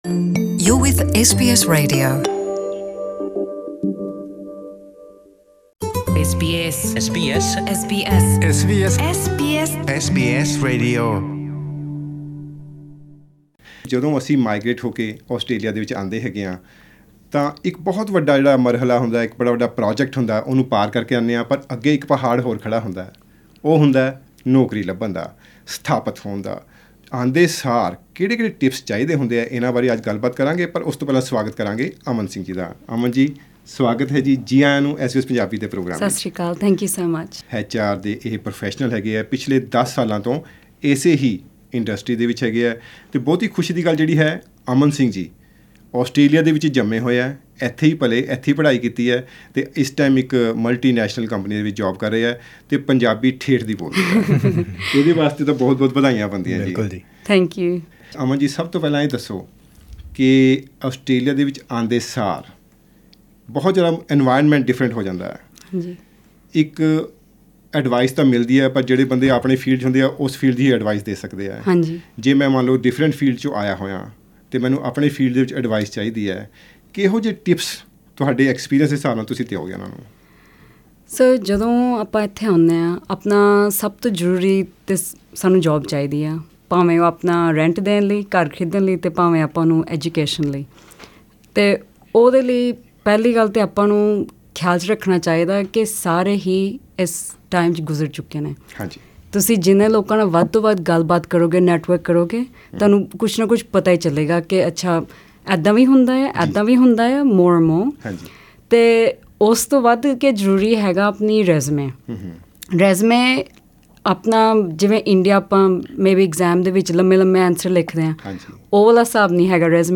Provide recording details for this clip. Facebook live session organised at SBS Punjabi studios in Sydney